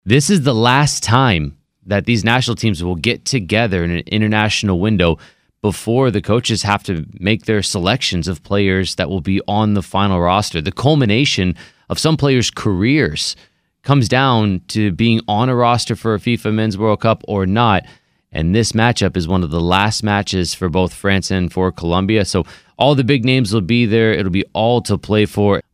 Soccer broadcaster